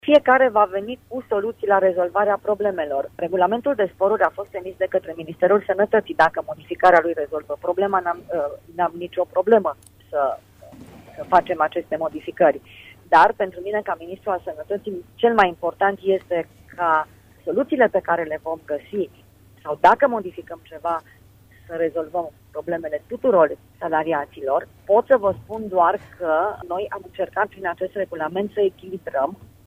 Ministrul sănătății, Sorina Pintea, a declarat, în emisiunea Imperativ, de la Radio Iași, că în vederea rezolvării problemelor legate de diminuarea veniturilor pentru anumite categorii salariale, prin limitarea sporurilor, vor fi analizate, la întâlnirea de pe 2 mai, toate propunerile.